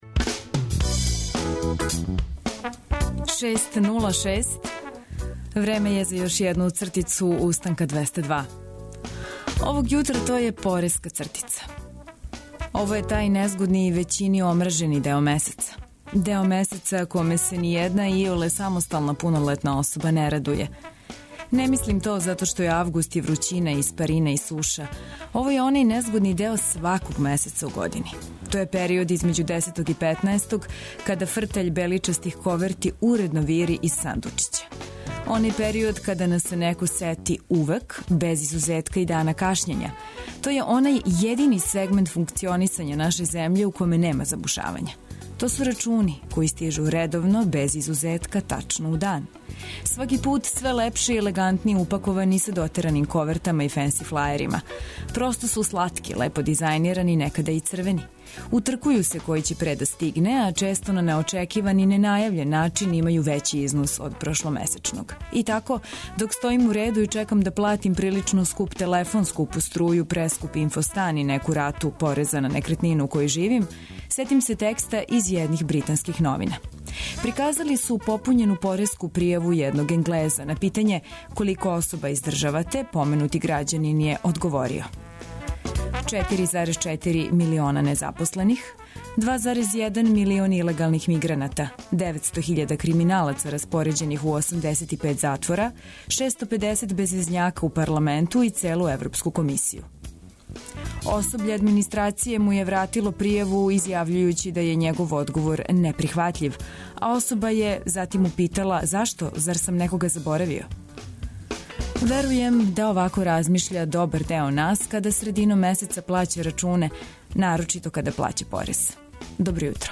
И четвртог дана радне недеље држимо се исте формуле у јутарњем програму - размрдавамо се уз добру музику, слушамо различите радијске прилоге, најновије вести и остале информације које је корисно чути ујутру.